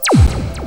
laser2.wav